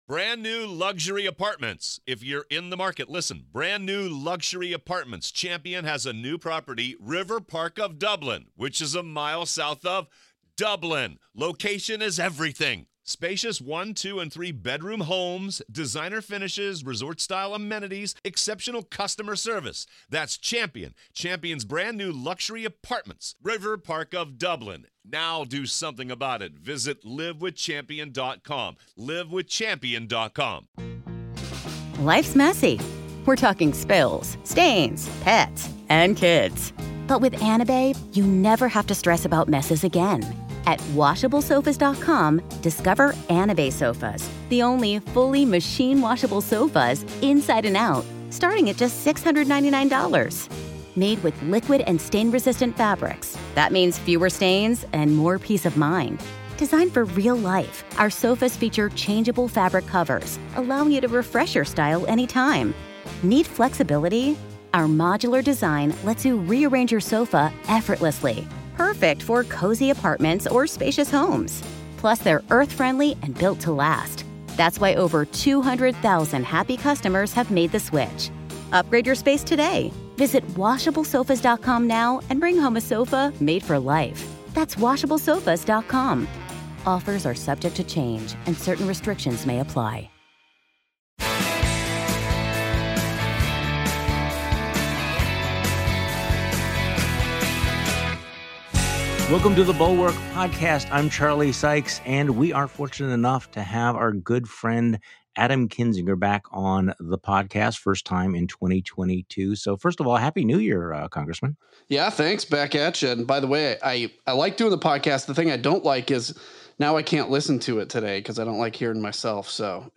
The behind-the-scenes evidence presented during the upcoming primetime Jan 6 committee hearings will be "compelling" and "shocking," and will be directed to an audience who's going to go out and vote. Rep. Adam Kinzinger joins Charlie Sykes on today's podcast.
Special Guest: Rep. Adam Kinzinger.